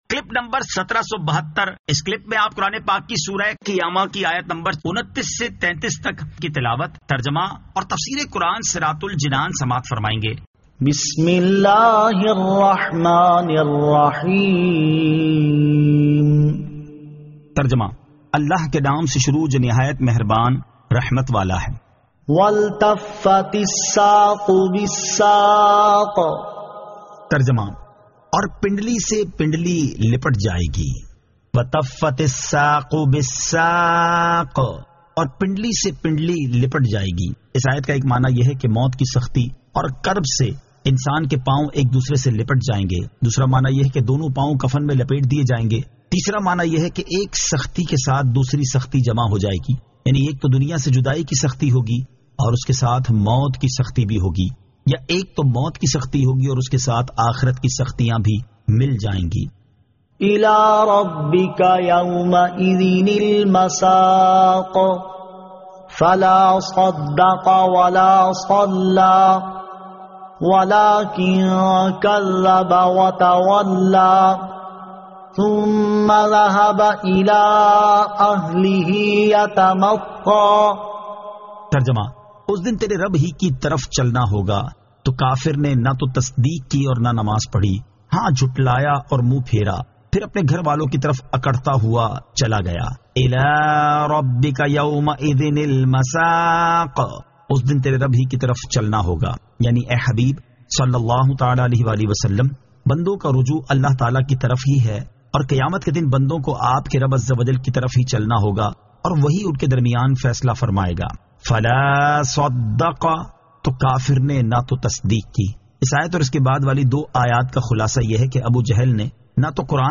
Surah Al-Qiyamah 29 To 33 Tilawat , Tarjama , Tafseer